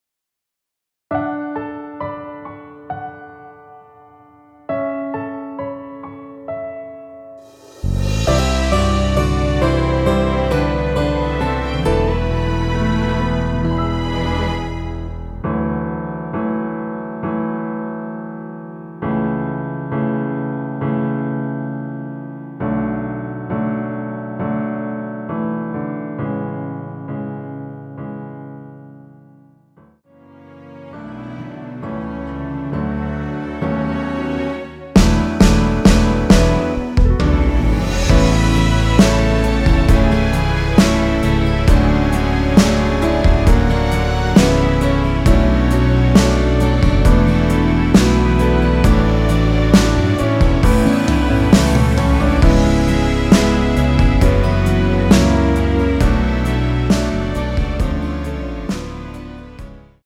원키에서(-3)내린 1절앞+후렴으로 진행되는 MR입니다.
앞부분30초, 뒷부분30초씩 편집해서 올려 드리고 있습니다.
중간에 음이 끈어지고 다시 나오는 이유는